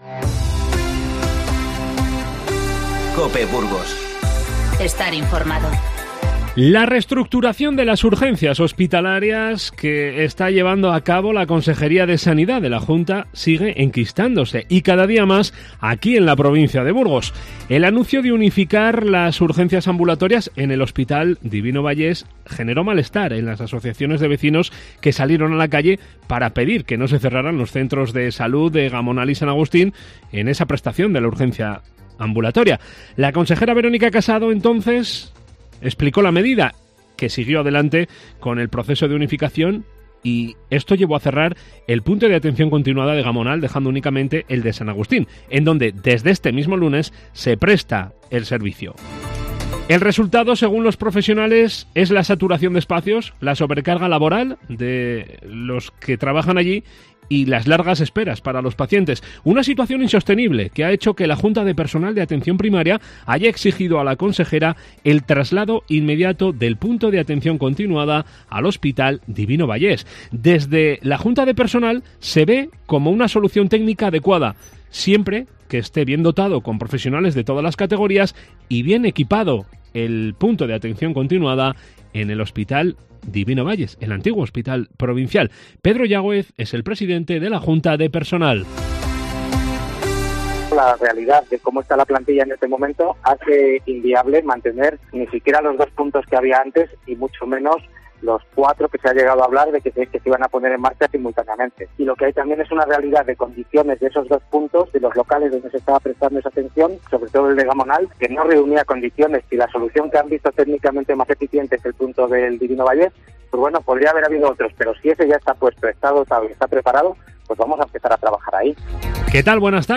Informativo 05-02-20